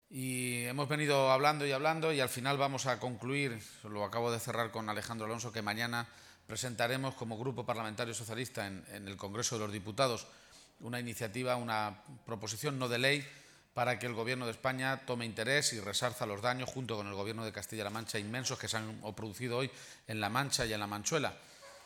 García-Page realizó este anuncio en una comida con militantes y simpatizantes en la localidad toledana de Torrijos, después de visitar esta mañana la zona afectada y mantener un encuentro con la alcaldesa de El Provencio.